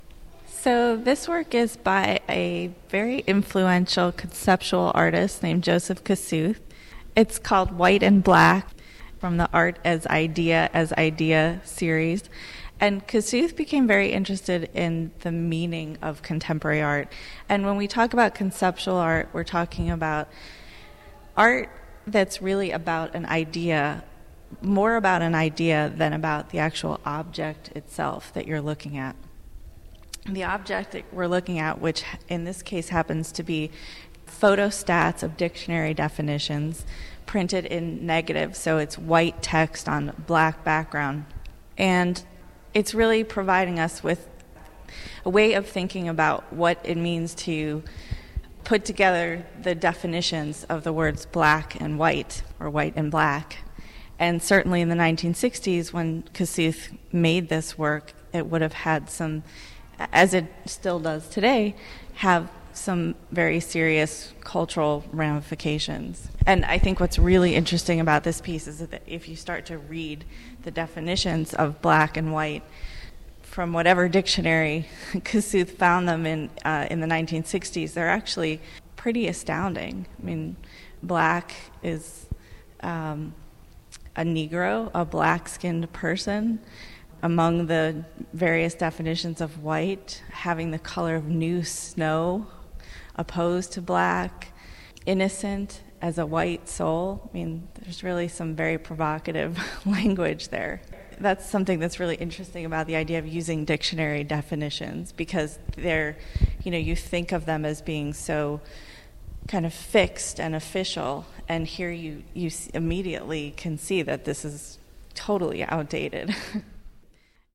These podcasts are taken from conversations in the Akron galleries